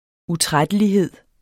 Udtale [ uˈtʁadəliˌheðˀ ]